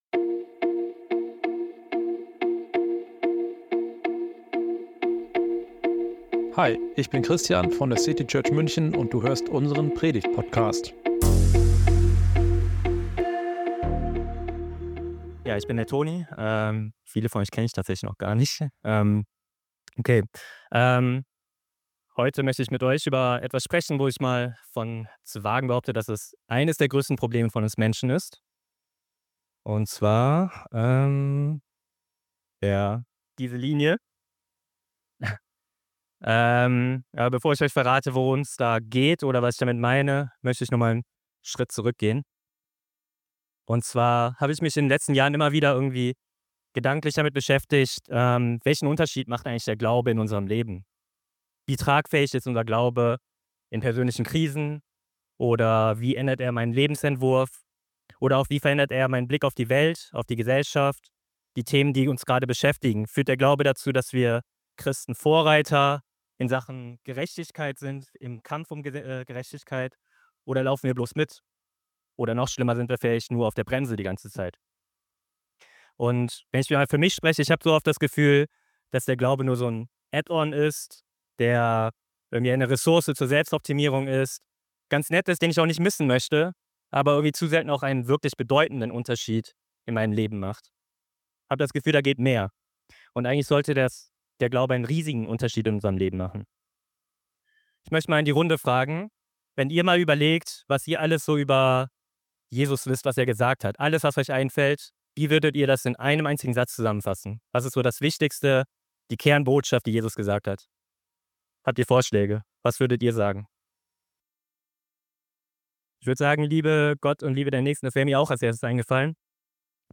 Diesen Sonntag wollen wir uns anhand der Bergpredigt anschauen, wie das Reich Gottes völlig neue Perspektiven eröffnet. Eine Predigt